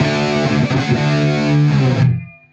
Index of /musicradar/80s-heat-samples/95bpm
AM_HeroGuitar_95-D02.wav